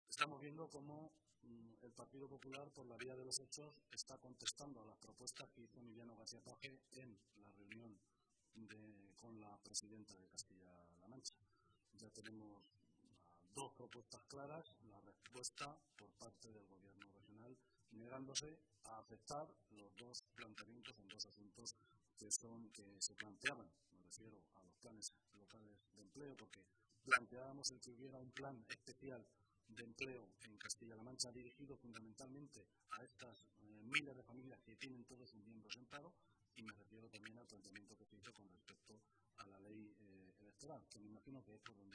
Así lo ha manifestado esta mañana, en rueda de prensa, el portavoz del Grupo Socialista, José Luis Martínez Guijarro.